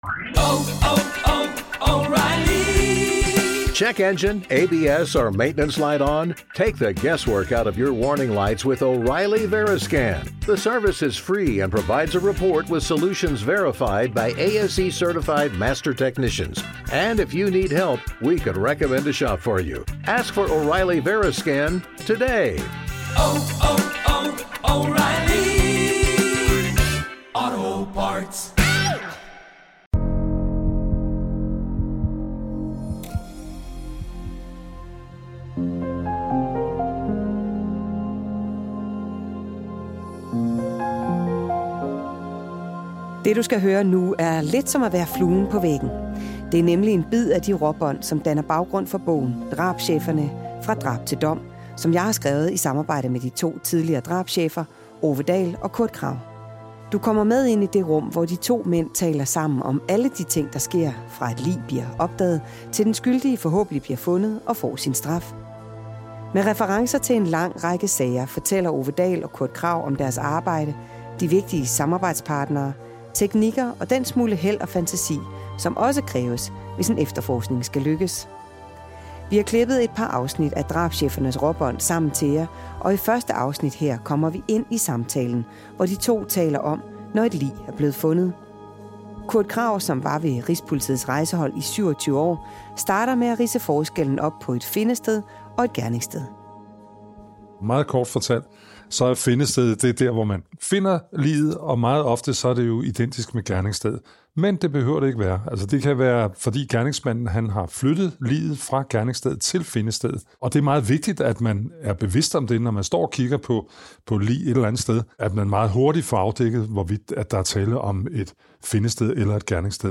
Du får lov at agere fluen på væggen og lytte med de uredigerede optagelser.